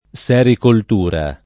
sericoltura [ SH rikolt 2 ra ] s. f.